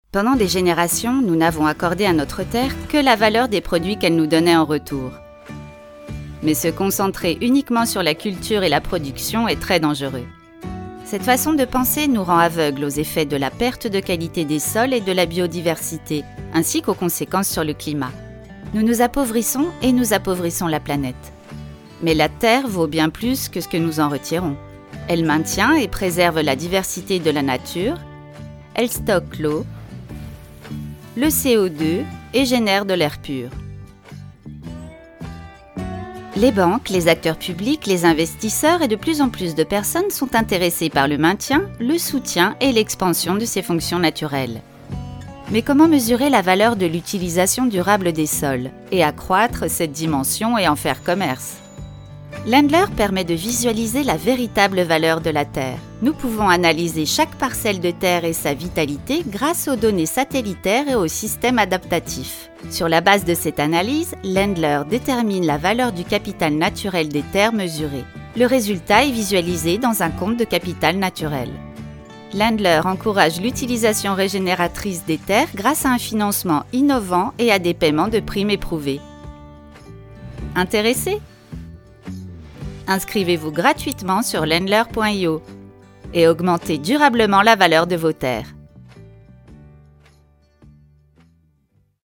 INSTITUTIONNEL (Landler) – informatif - positif